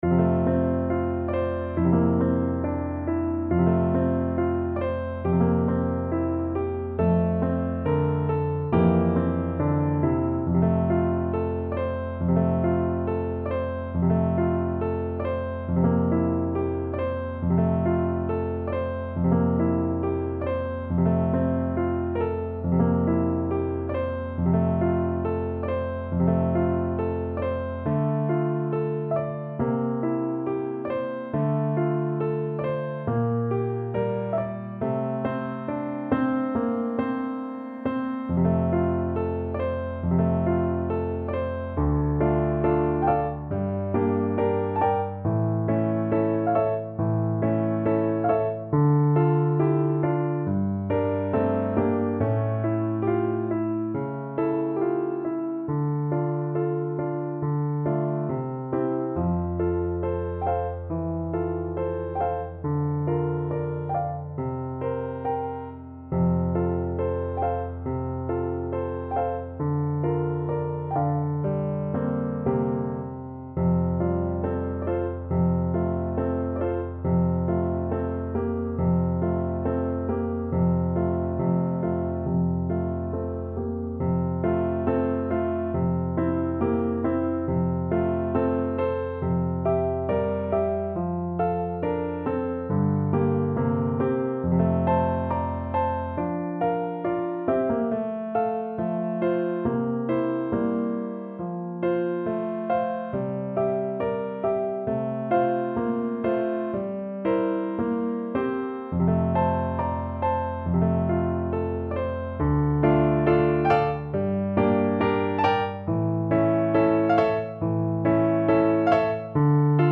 ~ = 69 Andante tranquillo
Classical (View more Classical Flute Music)